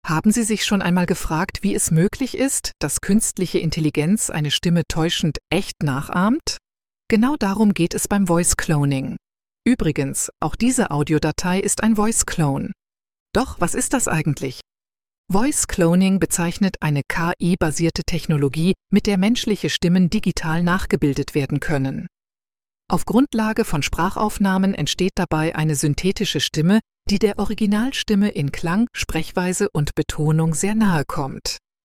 Auf Grundlage von Sprachaufnahmen entsteht dabei eine synthetische Stimme, die der Originalstimme in Klang, Sprechweise und Betonung sehr nahekommt.
Voice Cloning Beispiel
Beispiel_Voicecloning.mp3